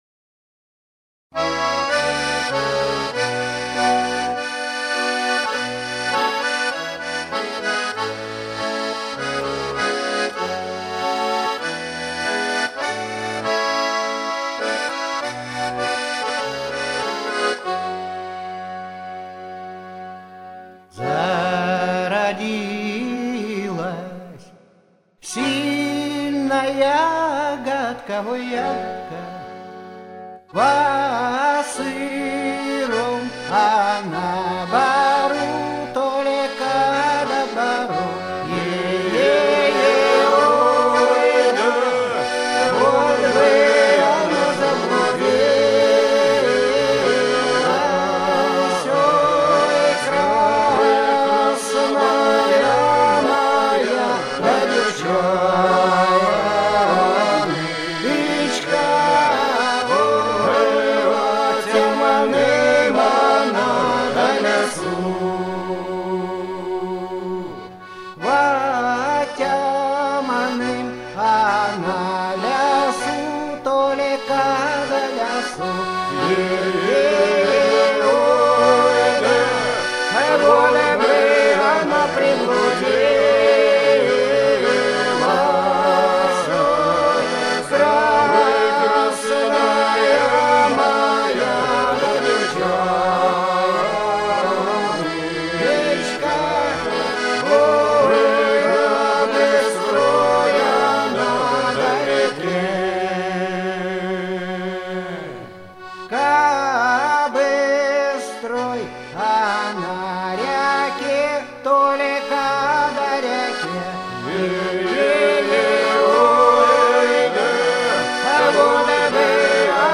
• Качество: Хорошее
• Жанр: Детские песни
Ансамбль казачьей песни